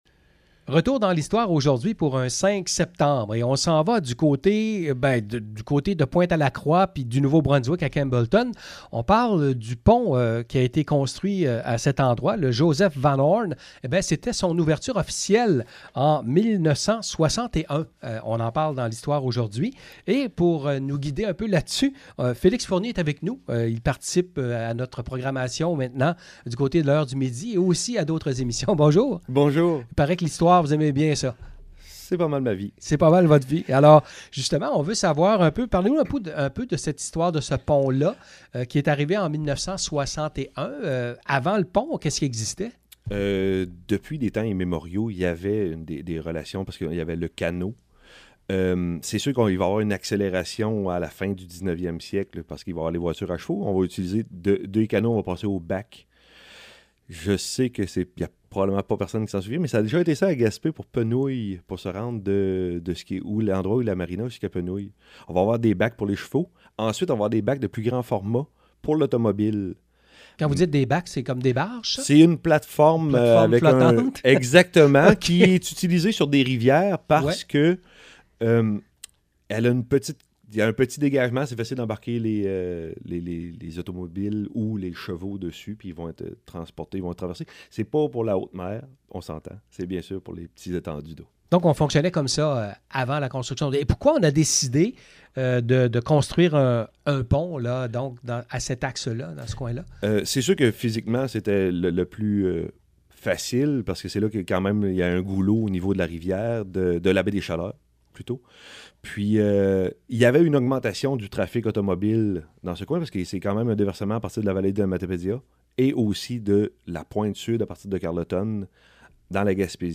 En 1961 c’était l’ouverture du pont interprovincial entre Pointe-à-la-Croix et Campbellton. Écoutez l’entrevue avec l’historien